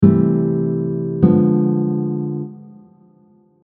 Barry Harris Major 6 diminished scale chords
Major-6th-diminished-chords-.mp3